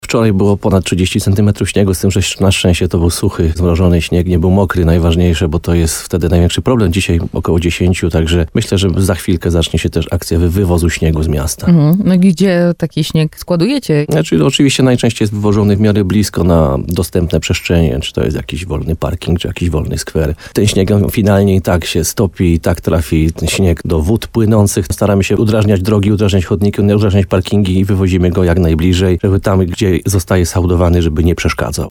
Jak zapewniał burmistrz Piotr Ryba w programie Słowo za Słowo na antenie RDN Nowy Sącz – bieżące odśnieżanie nie jest problemem, ale niebawem może być konieczne rozszerzenie prac o wywóz śniegu.